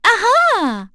Erze-Vox_Happy4.wav